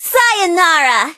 bibi_kill_vo_04.ogg